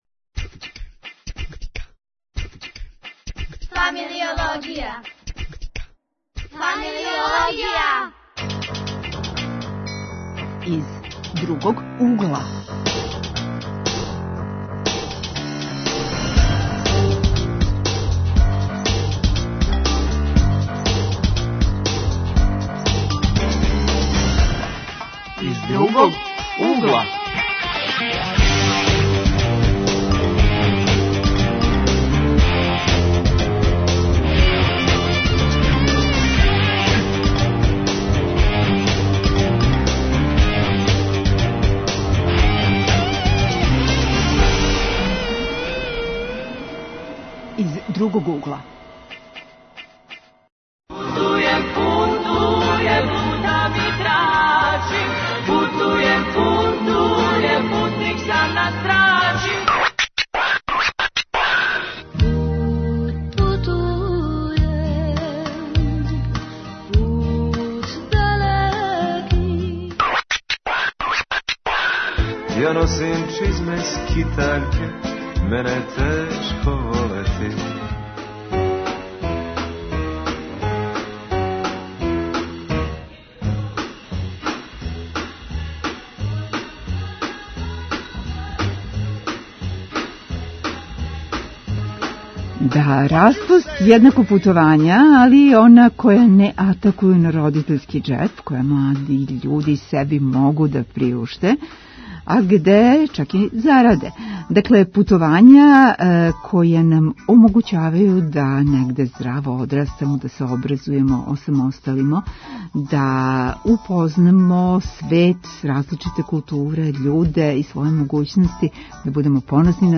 Гости у студију биће студенти који су лето провели по свету.